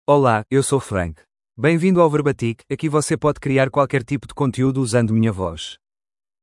FrankMale Portuguese AI voice
Frank is a male AI voice for Portuguese (Portugal).
Voice sample
Listen to Frank's male Portuguese voice.
Frank delivers clear pronunciation with authentic Portugal Portuguese intonation, making your content sound professionally produced.